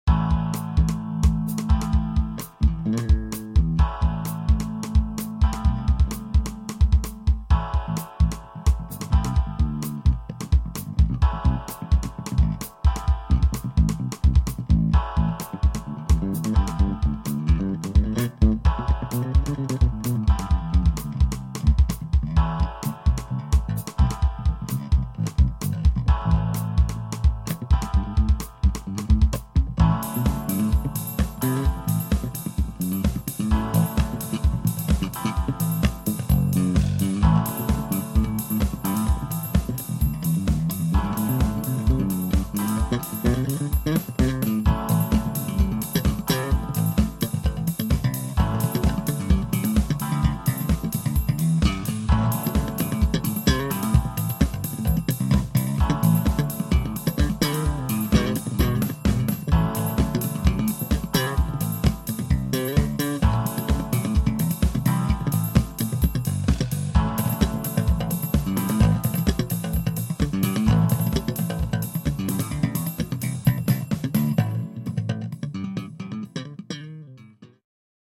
Jaaaj... Chvilku jsem si zamrdlal s cubasis pro ipad o steinbergu a musim rict woooow smile fakt jsem prekvapenej jak tam hezky fungujou simulace aparatu a lampovy nakresleni...strihani, crossfady... Cumim.
chlape , groove priam strieka z toho co tu davas . sedim s otvorenou hubou , zimomriavky az na chrbte a poklepkavam si.